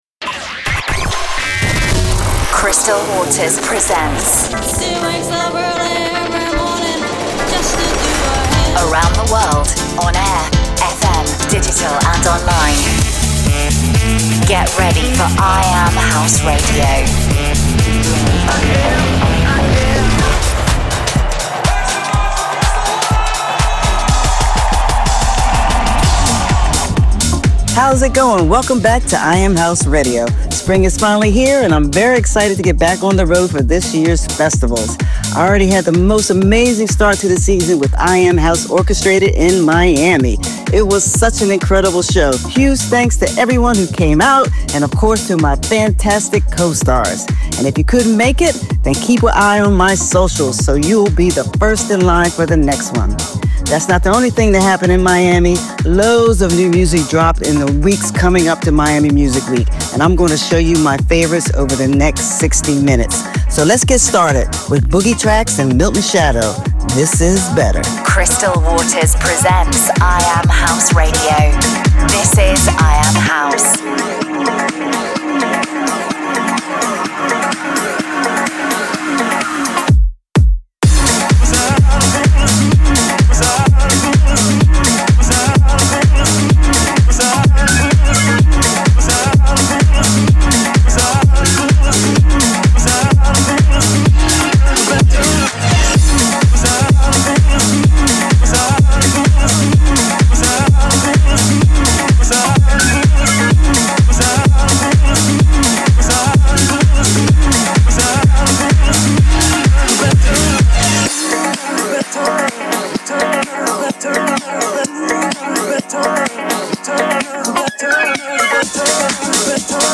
Playing the best new House Music from around the world. 01.